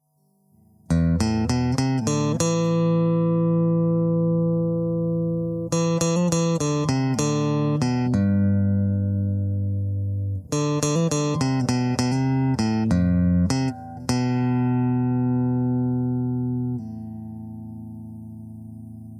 夏末吉他1
描述：吉他
Tag: 100 bpm Acoustic Loops Guitar Electric Loops 1.62 MB wav Key : A